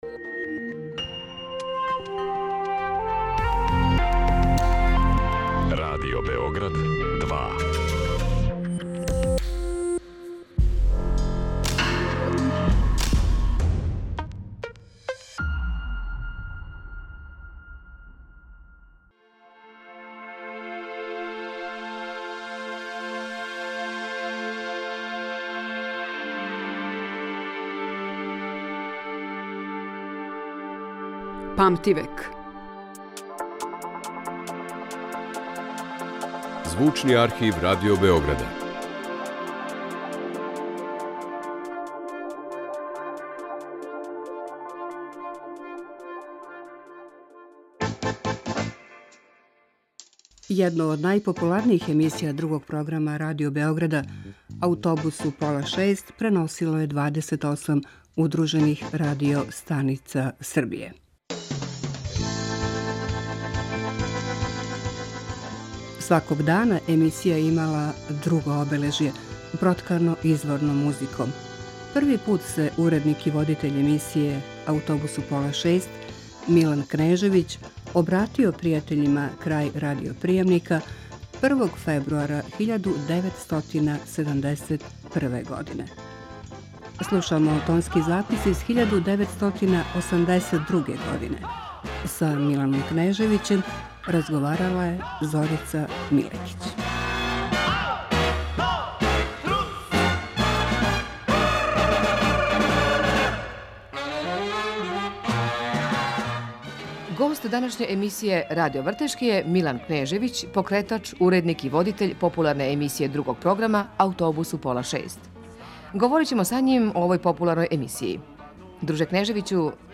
Сваког дана емисија је имала друго обележје и све то уз изворне музике.